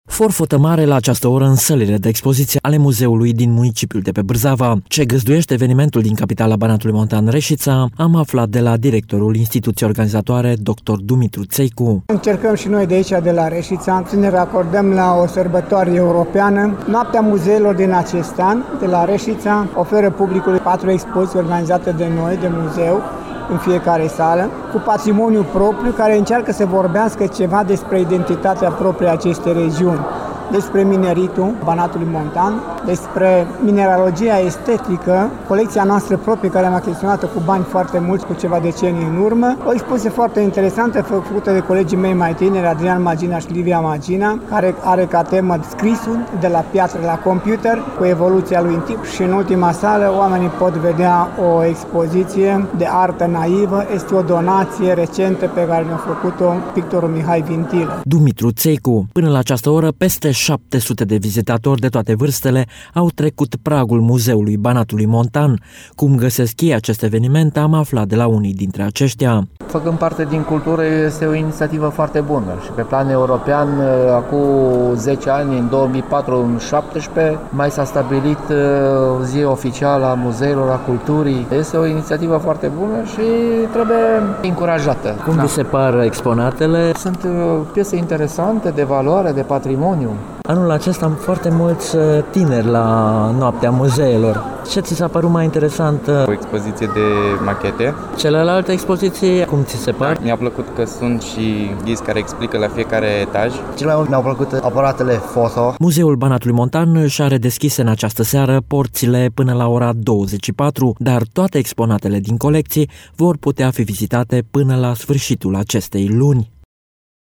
Reşiţa s-a alăturat în această seară evenimentului european de promovare a culturii sub genericul “Noaptea Muzeelor”.